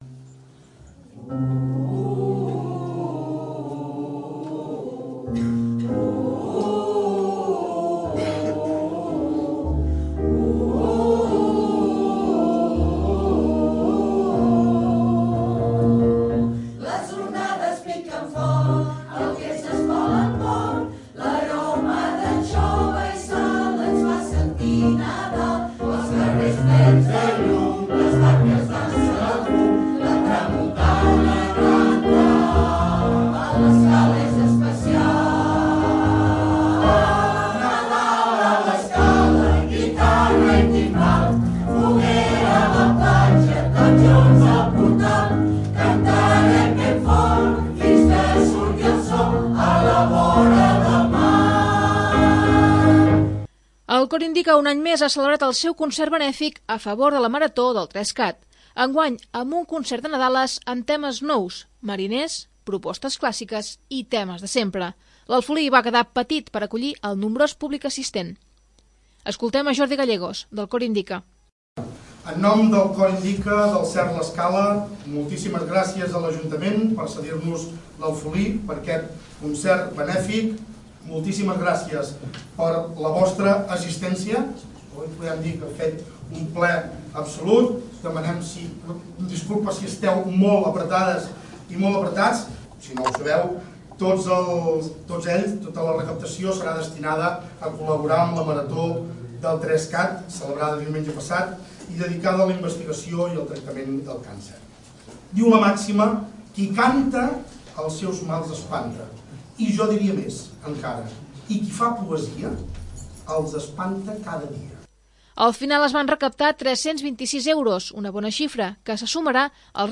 Enguany amb un concert de Nadales amb temes nous, mariners, propostes clàssiques i temes de sempre. L'Afolí va quedar petit per acollir el nombrós públic assistent.